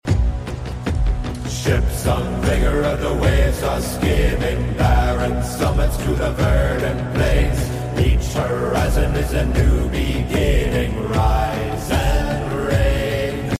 Can you hear the call of the Viking horn?